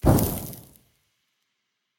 Minecraft Version Minecraft Version snapshot Latest Release | Latest Snapshot snapshot / assets / minecraft / sounds / mob / zombie / infect.ogg Compare With Compare With Latest Release | Latest Snapshot
infect.ogg